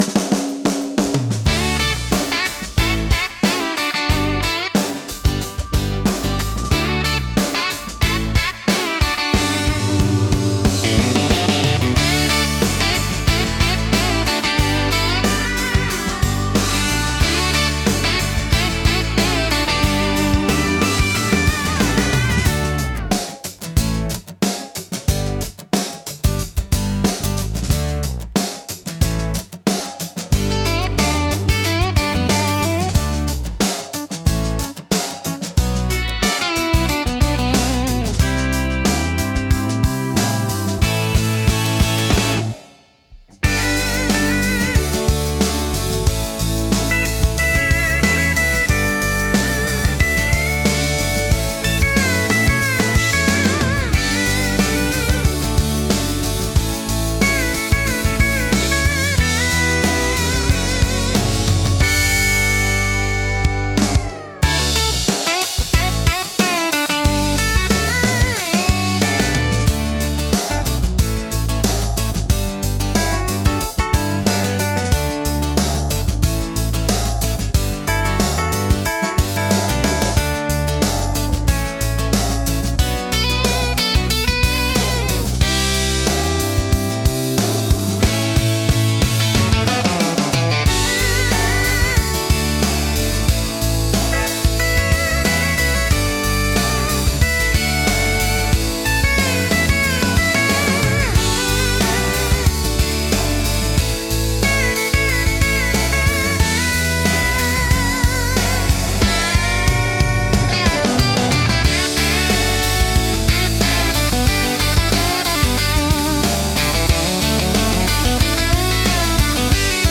聴く人に快適で穏やかな気持ちをもたらし、ナチュラルで親近感のある空気感を演出します。